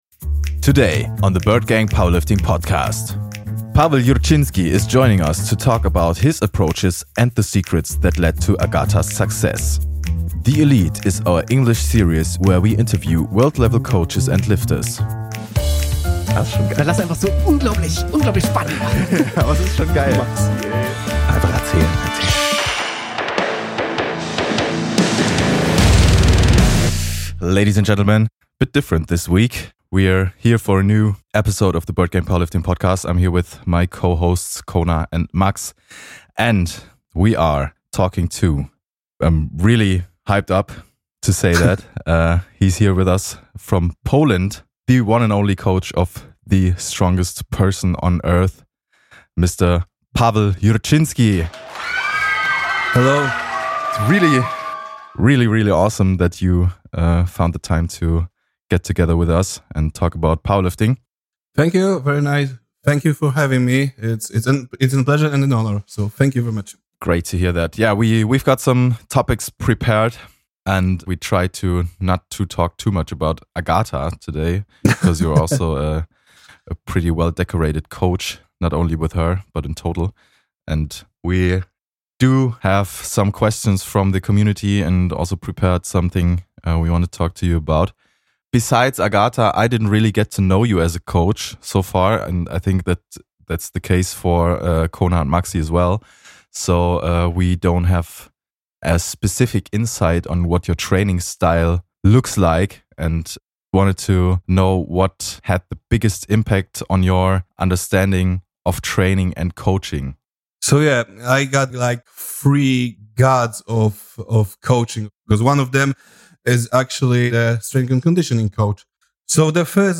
THE ELITE is our english series where we interview world level coaches and lifters.